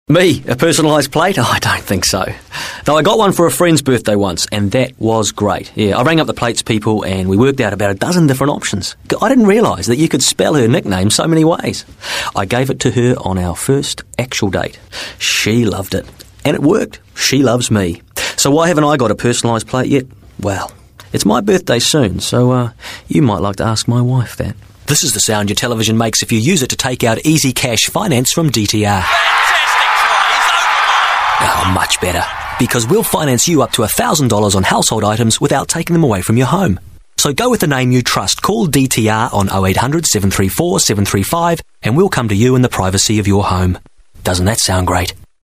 Voice Sample: Voice Demo
We use Neumann microphones, Apogee preamps and ProTools HD digital audio workstations for a warm, clean signal path.